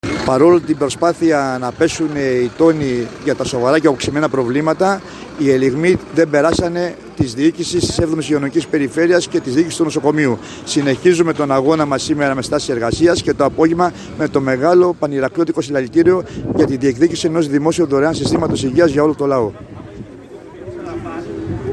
Και σήμερα οι εργαζόμενοι του ΠαΓΝΗ πραγματοποίησαν νέα 2ωρη στάση εργασίας και